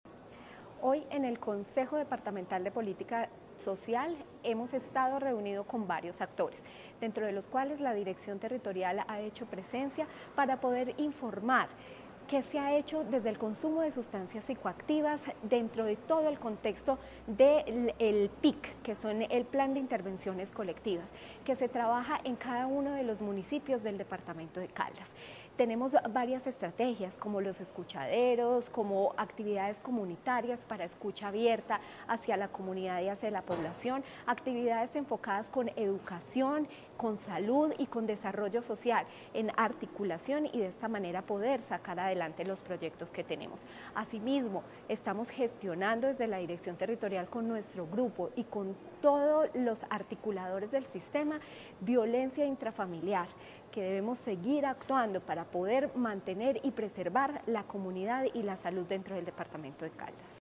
Natalia Castaño Díaz, directora de la Territorial de Salud de Caldas.